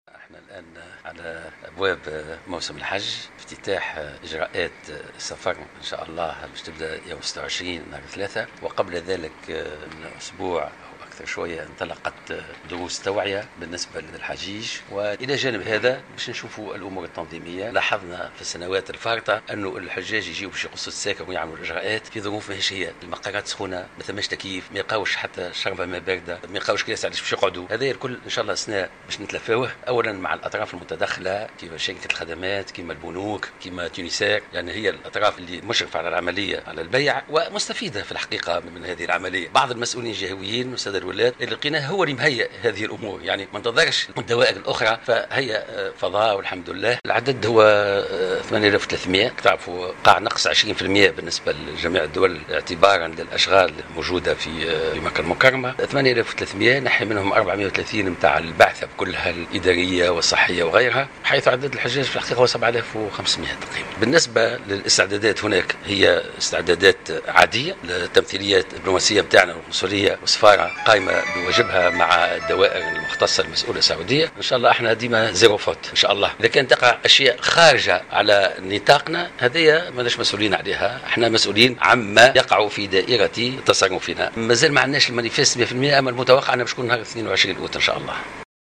وأضاف الوزير في تصريح لمراسل "الجوهرة أف أم" على هامش زيارته لولاية المهدية أن عدد أعضاء البعثة التونسية المرافقة للحجيج بلغ 430 شخصا، متوقعا انطلاق أولى الرحلات نحو البقاع المقدسة يوم 22 أوت القادم.